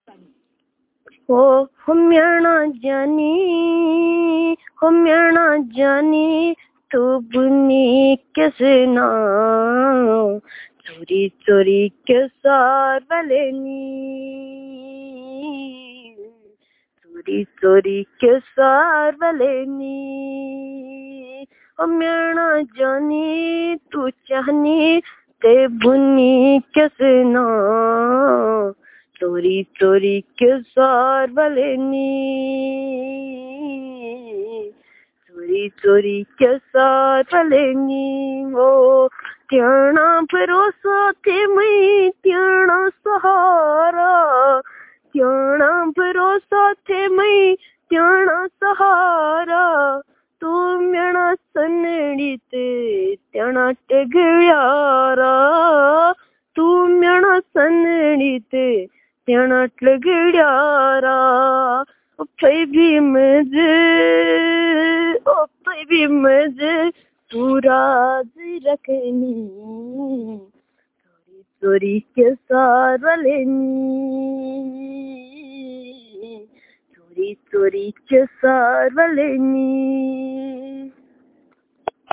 Performance of folk songs